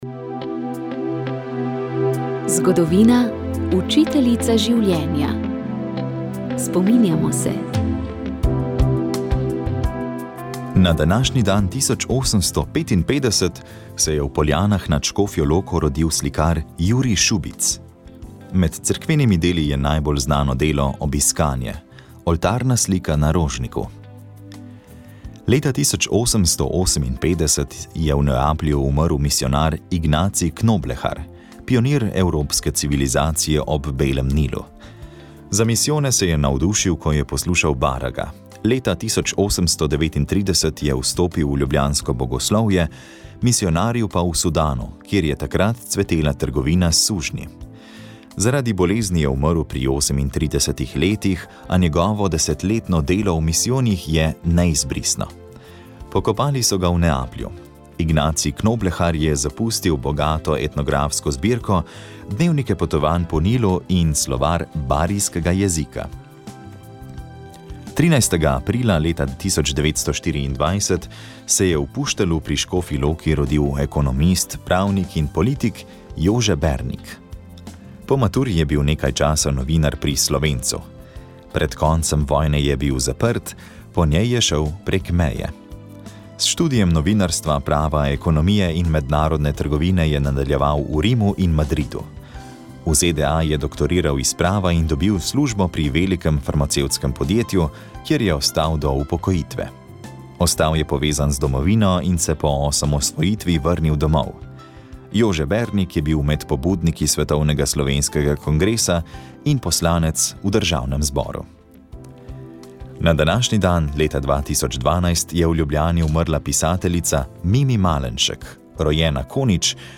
Poročali smo, kako gre romarjem, ki so ob 800-letnici Sončne pesmi vzeli v roke popotno palico in se peš odpravili iz Goričkega v Piran. Približno na polovici poti jih je naš mikrofon ujel pri kapucinih v Štepanji vasi.